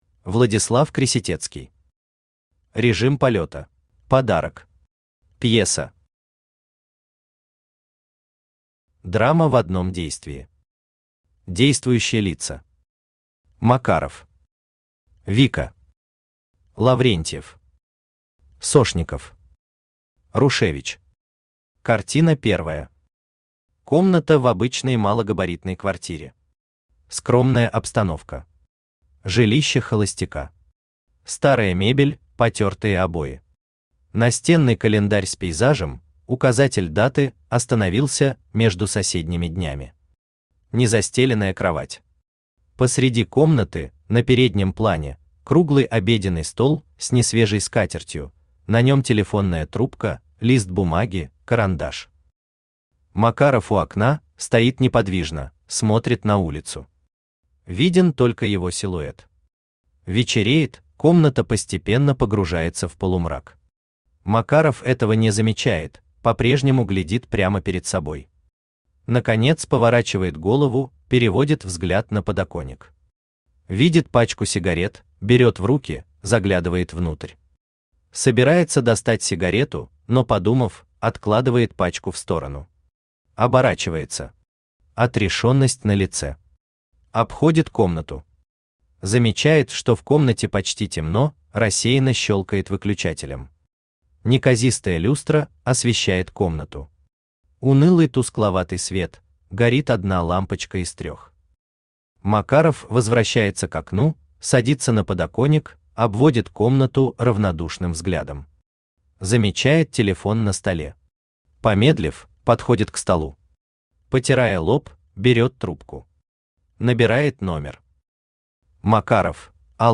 Aудиокнига Режим полёта Автор Владислав Крисятецкий Читает аудиокнигу Авточтец ЛитРес.